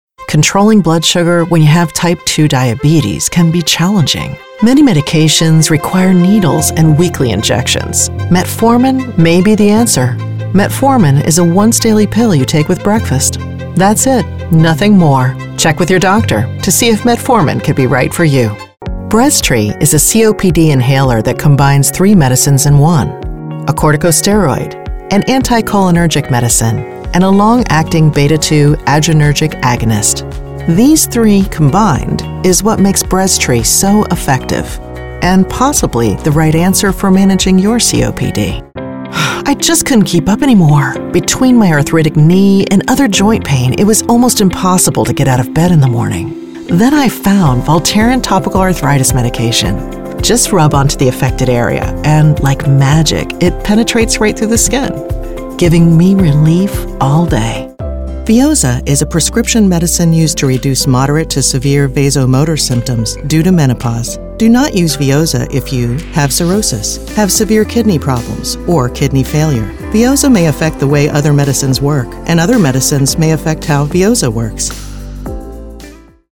Female Voice Over, Dan Wachs Talent Agency.
Agile, Passionate, Mama Bear